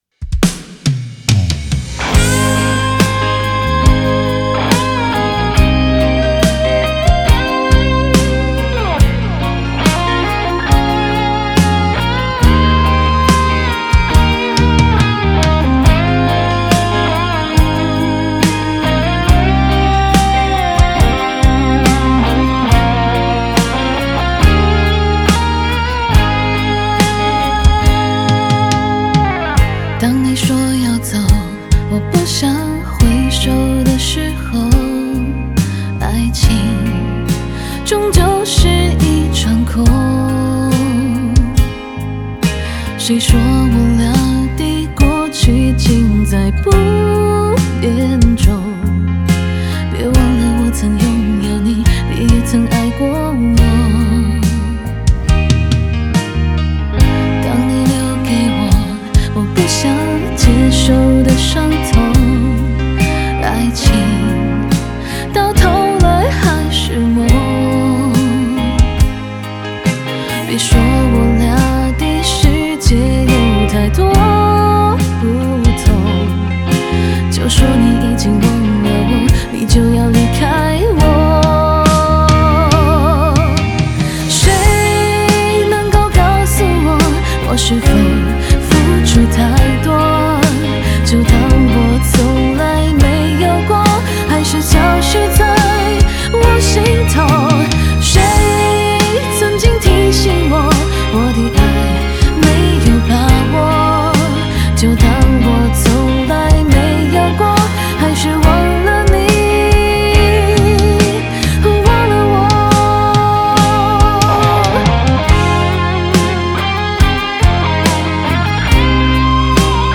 Ps：在线试听为压缩音质节选，体验无损音质请下载完整版
女声版
吉他
Bass
和声